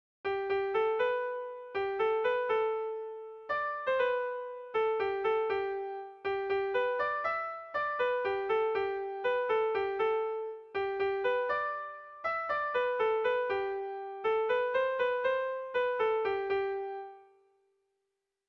Melodías de bertsos - Ver ficha   Más información sobre esta sección
Sentimenduzkoa
AB1B2